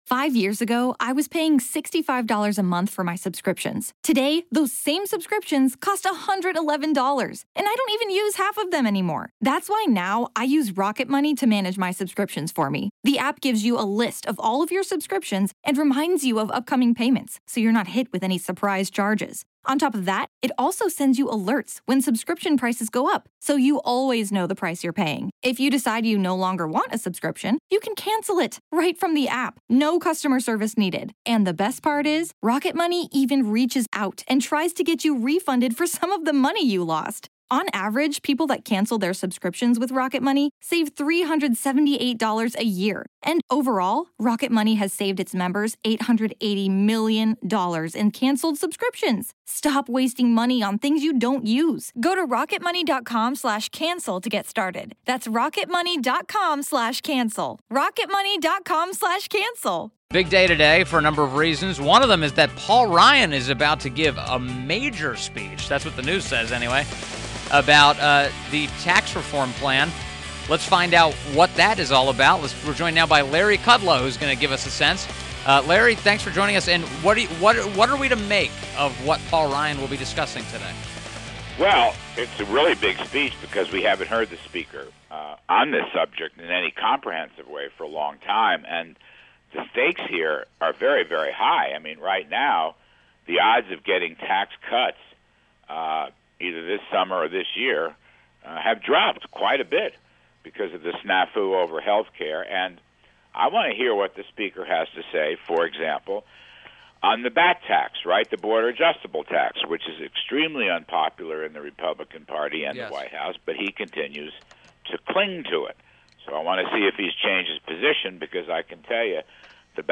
WMAL Interview - LARRY KUDLOW 6.20.17